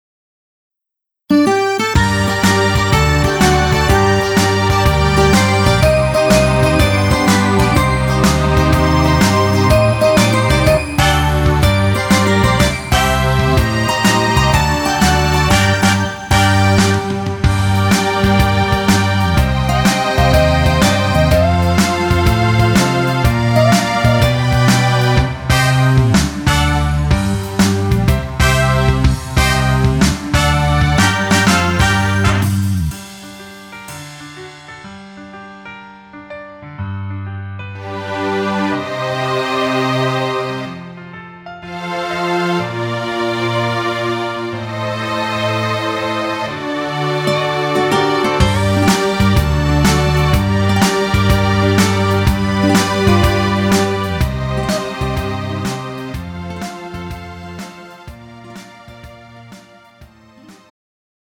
음정 여자키 3:11
장르 가요 구분 Pro MR